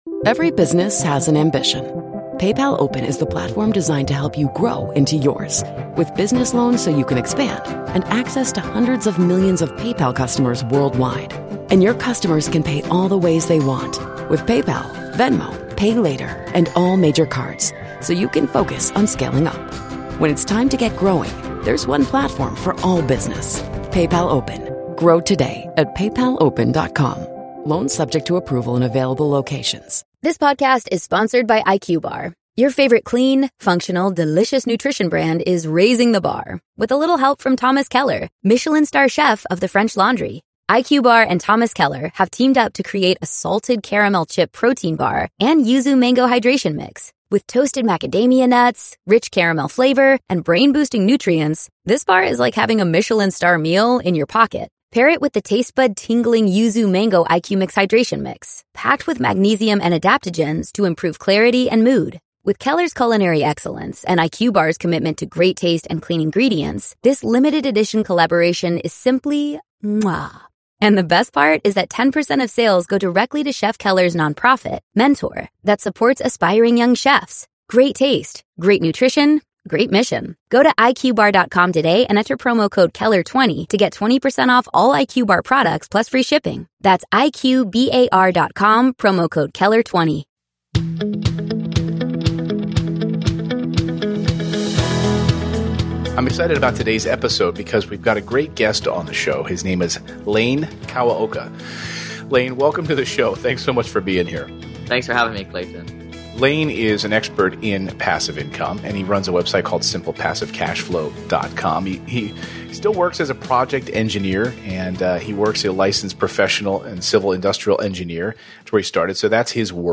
EP251: Don't Buy A Class Properties, Do This Instead - Interview